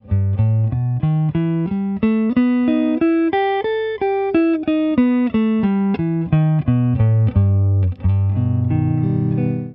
La pentatonique harmonique & mélodique T b3 P4 P5 M7
La pentatonique harmonique & mélodique:
Penta_harmonique_2e_position.wav